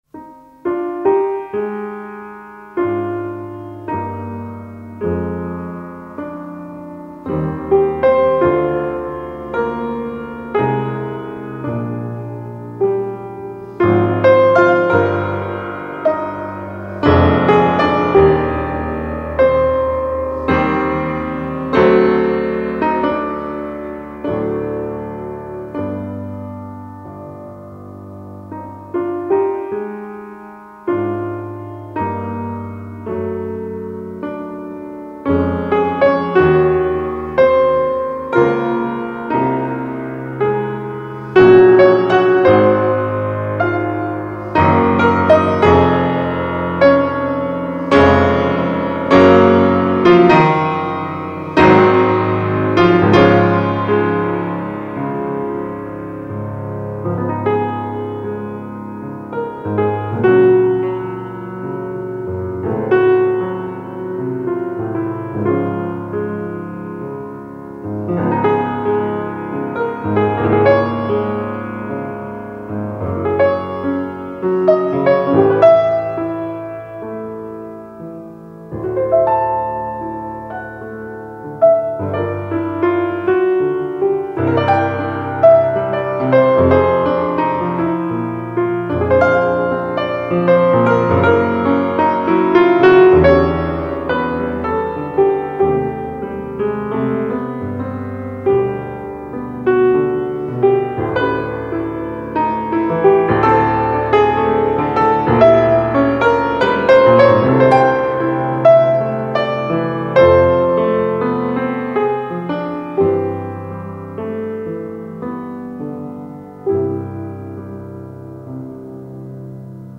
Egy rövid zongoradarabja itt hallható: